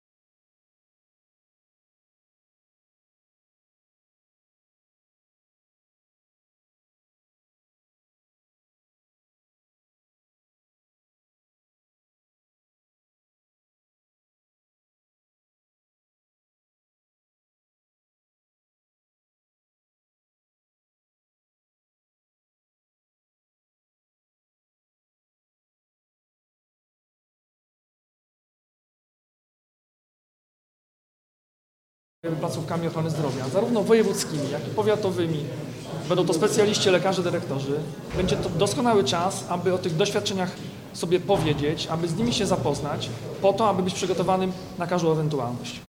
O tym rozmawiali eksperci podczas konferencji na Politechnice Wrocławskiej pod hasłem: „Bezpieczeństwo i funkcjonowanie podmiotów leczniczych w sytuacjach kryzysowych na podstawie doświadczeń Ukrainy. Zabezpieczenie medyczne wojsk na potrzeby obronne w Polsce”.
– W obliczu obecnej sytuacji międzynarodowej i geopolitycznej konieczne jest nie tylko rozwijanie infrastruktury i kształcenie kadr medycznych, ale również przygotowanie systemu ochrony zdrowia na sytuacje kryzysowe – mówi Jarosław Rabczenko, członek Zarządu Województwa Dolnośląskiego.